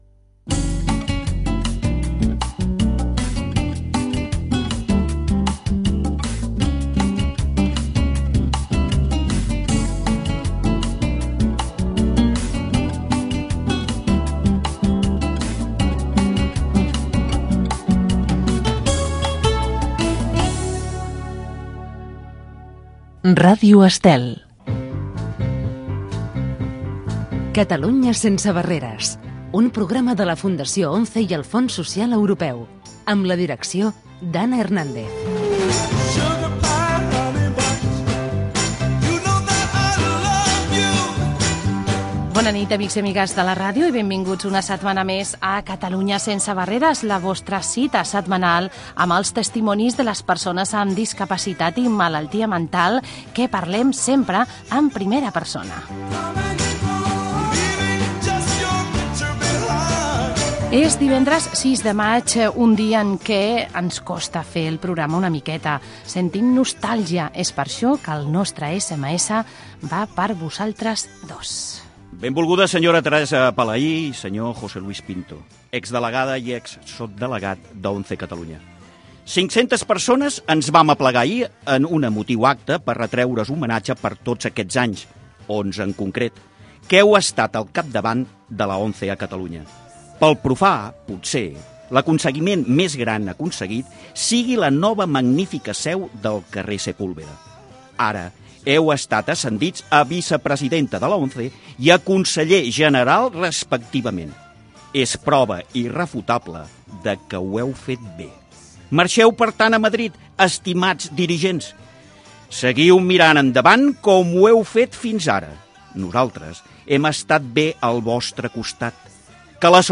Sintonia de l'emissora, careta, presentació
Gènere radiofònic Divulgació